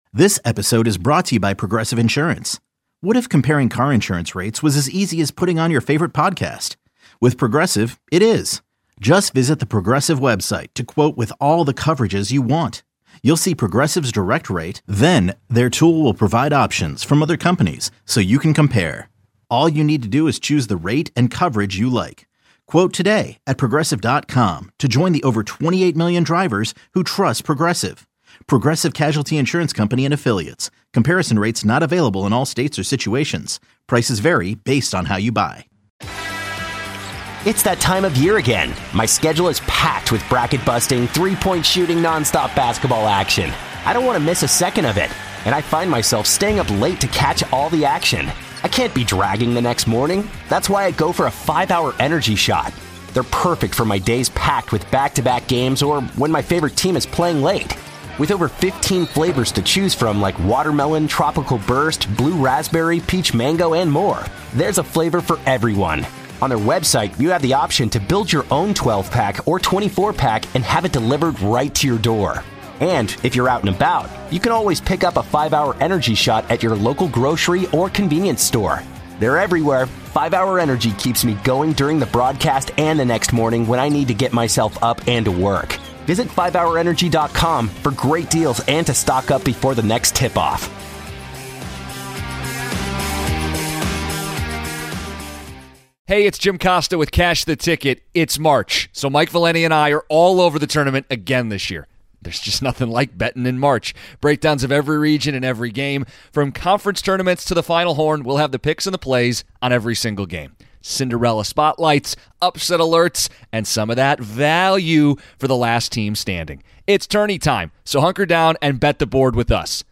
For more than 25 years, The Junks have owned Washington D.C. sports radio, covering Commanders, Nationals, Capitals, Wizards, Hokies, Terrapins, and Hoyas news.